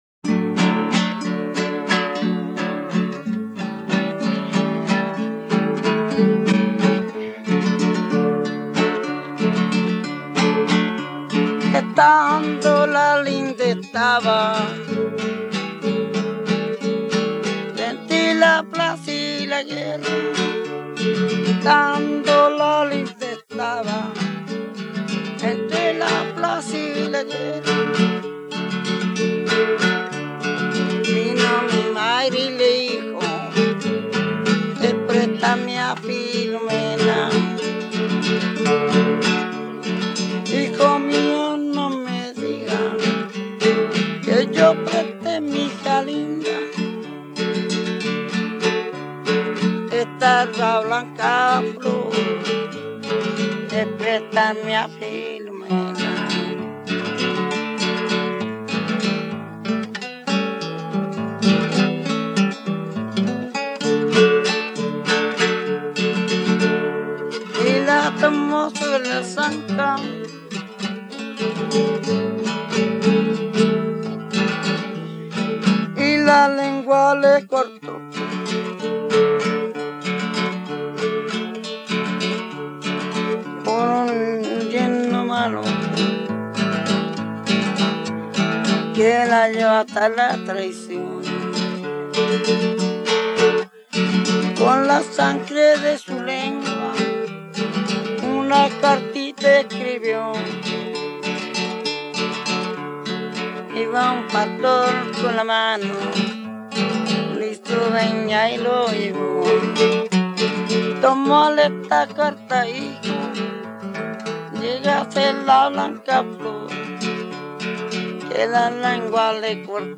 Romance en forma de vals que trata el tema tradicional de "Blanca Flor y Filomena".
quien se acompaña por una guitarra afinada con la tercera alta.
Música tradicional
Folklore
Vals